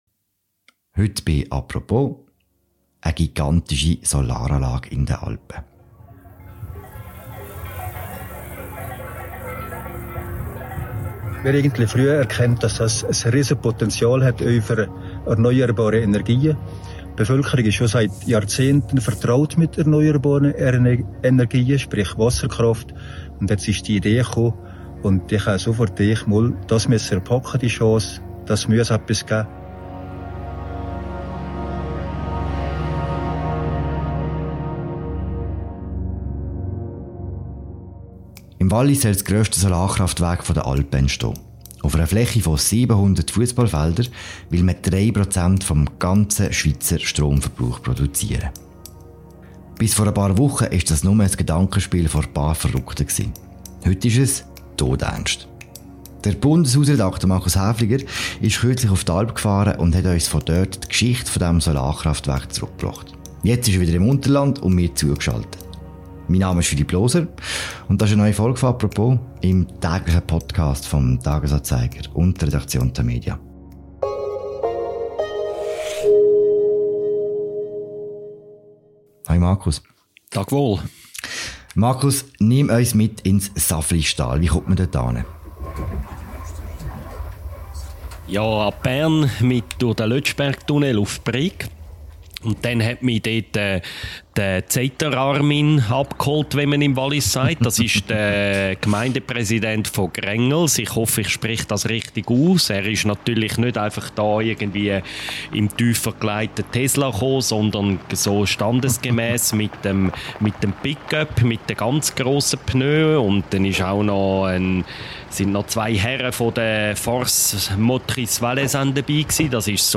«Apropos» besucht die – sonst sonnige – Alp im Nebel.